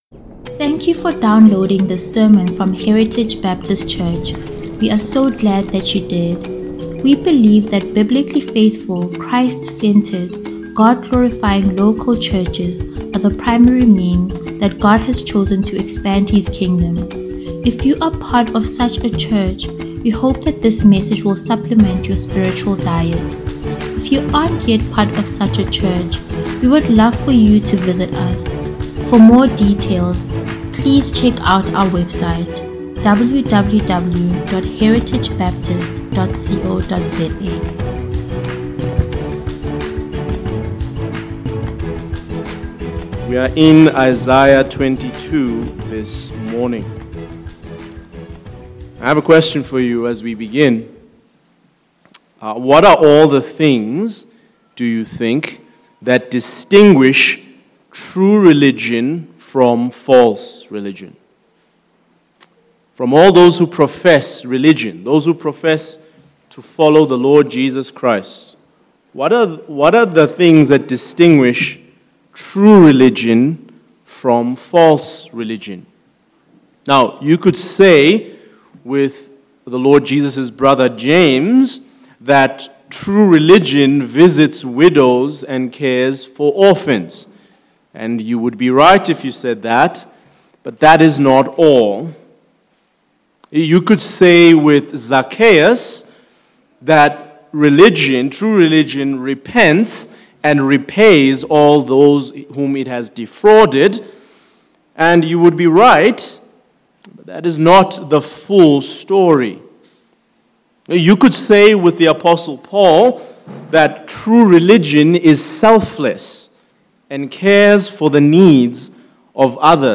Heritage Sunday Sermons